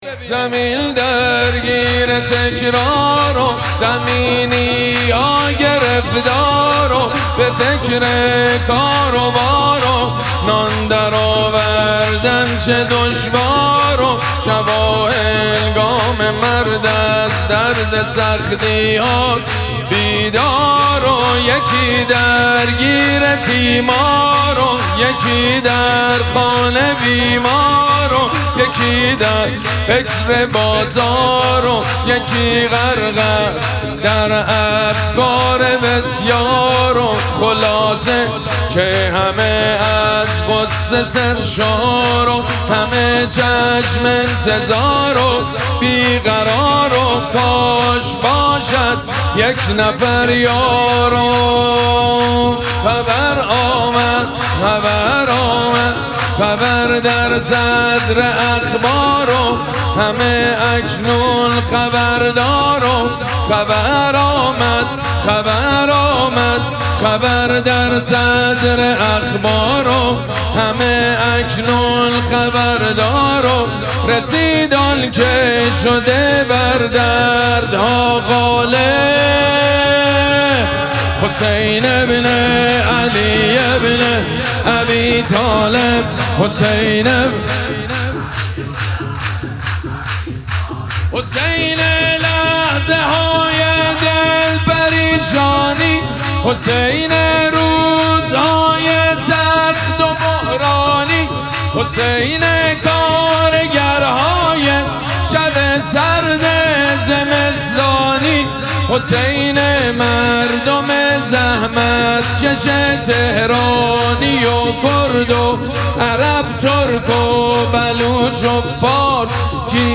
شب دوم مراسم جشن ولادت سرداران کربلا
سرود
حاج سید مجید بنی فاطمه